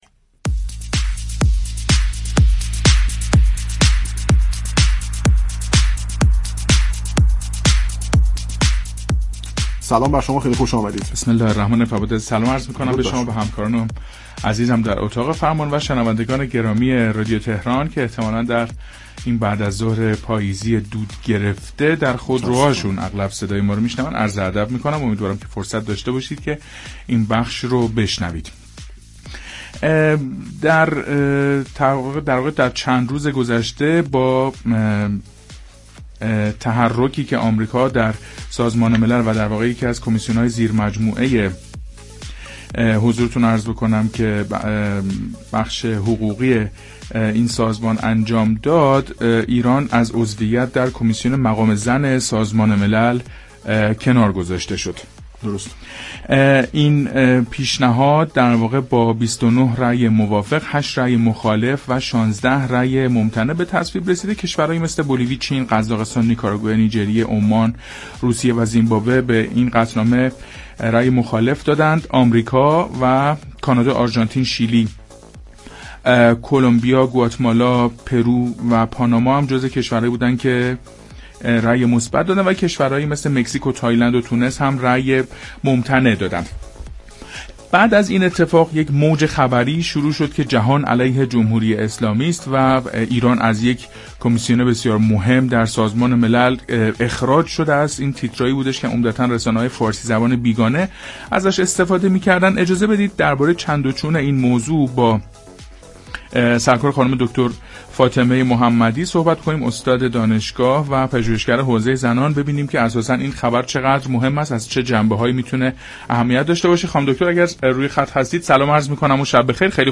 در گفت و گو با "تهران من" رادیو تهران